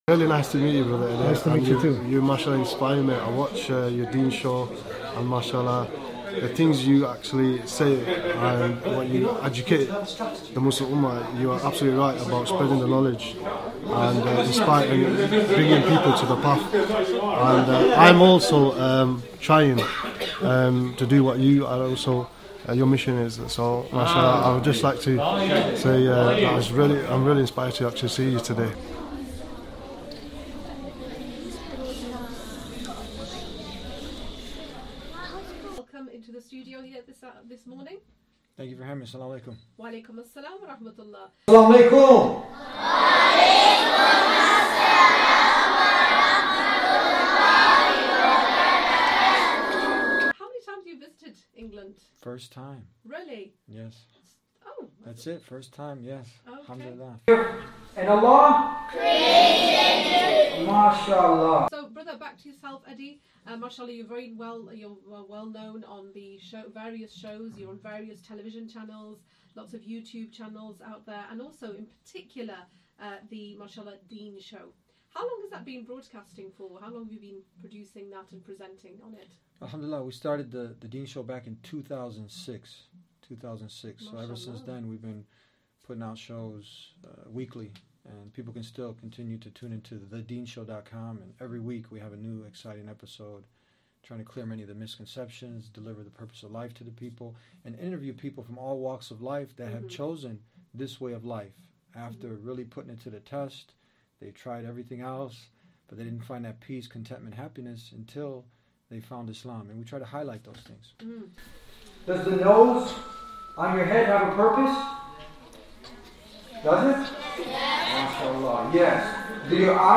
The rules were simple: when a real, wholesome food was named, the kids would shout “ding ding ding!” and when fake, processed food was named, they would call it out as “fake food.” Bananas, dates, and chicken earned enthusiastic approval, while Coca-Cola, Snicker bars, and Pop-Tarts were immediately identified as fake food.